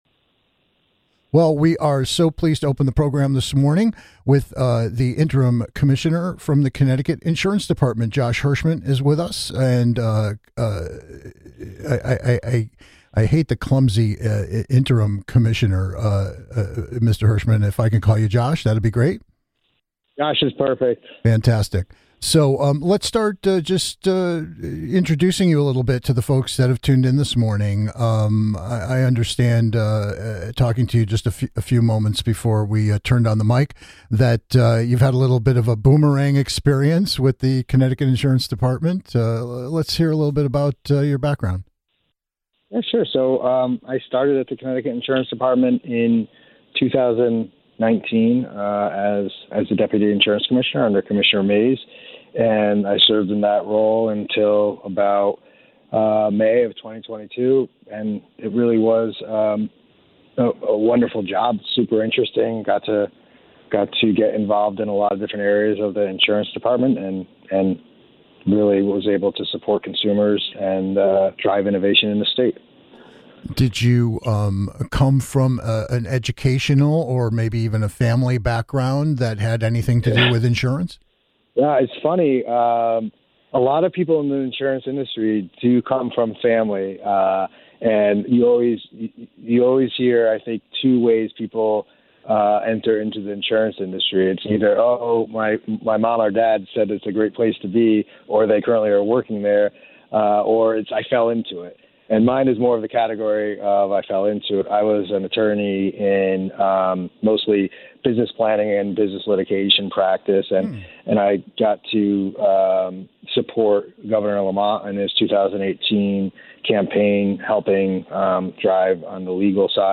Headliner Embed Embed code See more options Share Facebook X Subscribe For the People is sharing a ton of accurately sourced details about insurance as we welcome the Connecticut Insurance Department's Interim Commissioner, who will be covering a range of related subjects and reminding you about his department's brand new online Consumer Information Center.